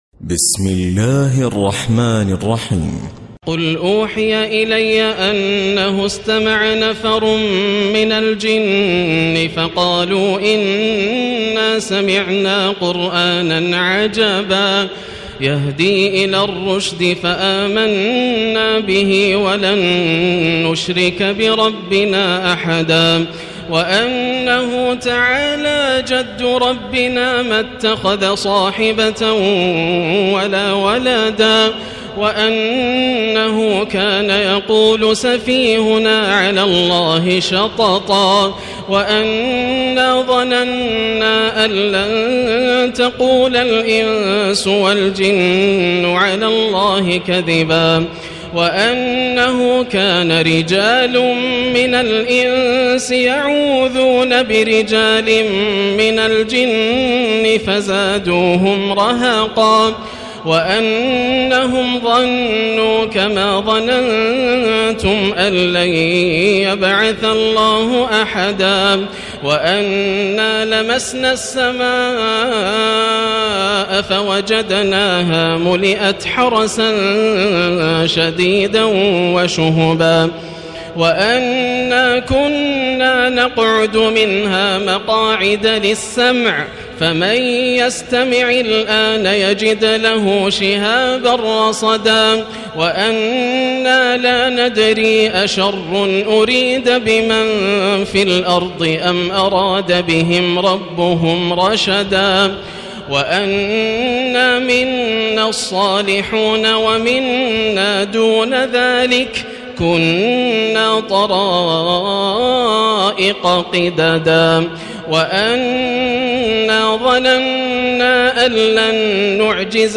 الليلة الثامنة والعشرين من تراويح رمضان 1437هـ > الليالي الكاملة > رمضان 1437هـ > التراويح - تلاوات ياسر الدوسري